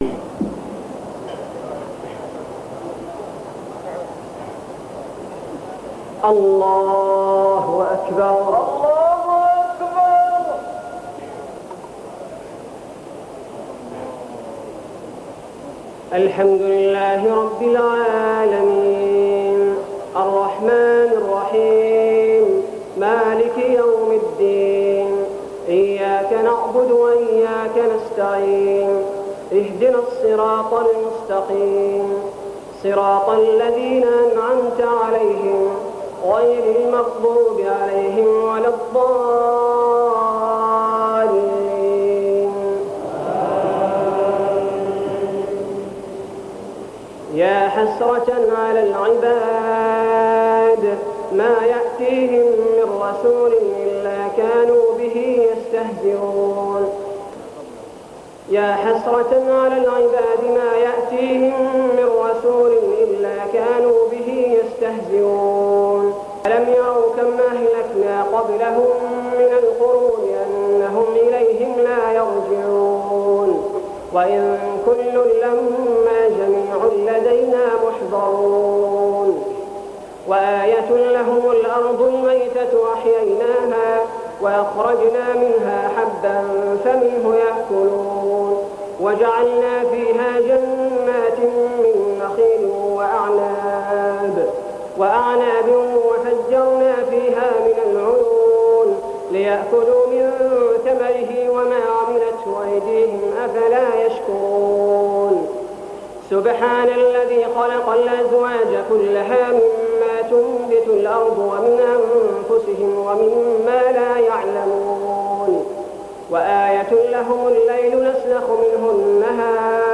تراويح ليلة 22 رمضان 1411هـ من سور يس (30-83) و الصافات (1-138) Taraweeh 22 st night Ramadan 1411H from Surah Yaseen and As-Saaffaat > تراويح الحرم المكي عام 1411 🕋 > التراويح - تلاوات الحرمين